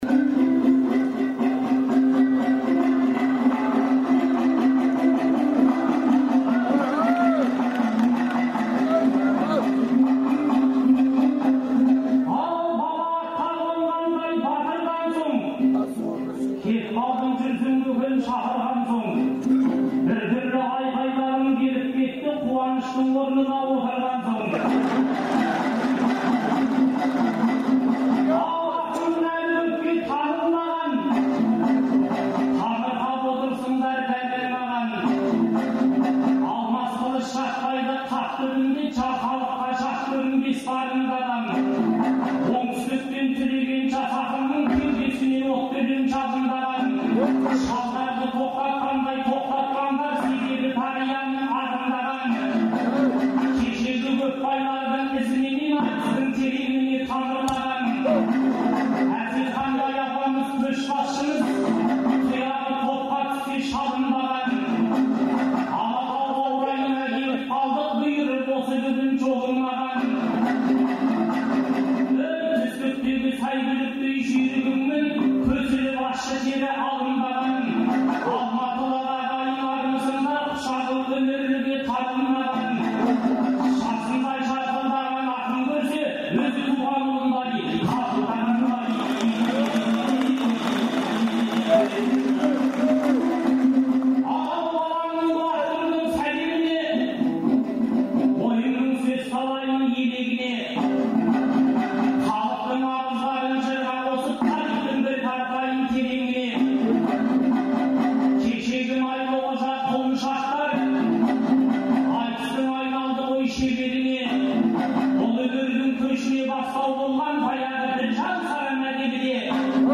Маусымның 2-күні Республика сарайында өткен «Ретро айтыстың»